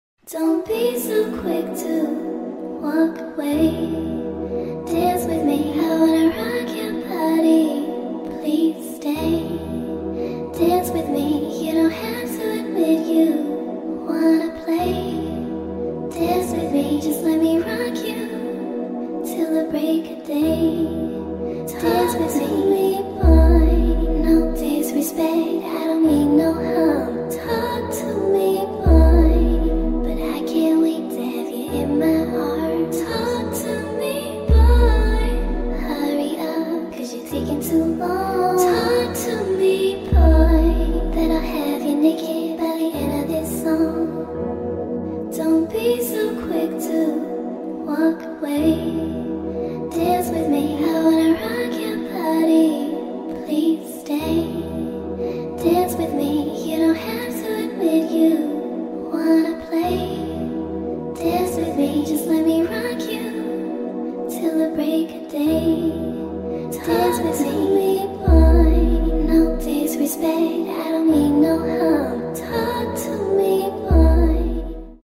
🩶 Chevrolet Corvette C7 🩶 Sound Effects Free Download